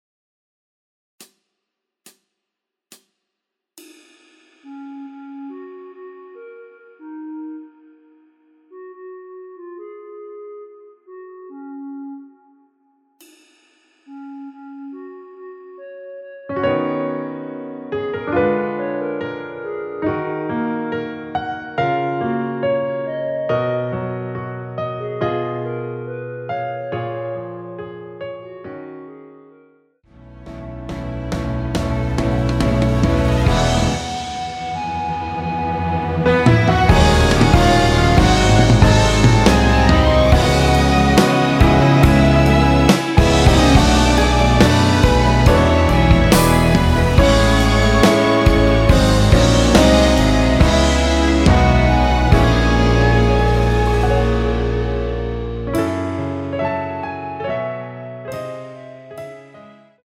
원키에서(-4)내린 멜로디 포함된 MR입니다.
F#
앞부분30초, 뒷부분30초씩 편집해서 올려 드리고 있습니다.
중간에 음이 끈어지고 다시 나오는 이유는